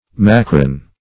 Macron \Ma"cron\, n. [NL., fr. Gr.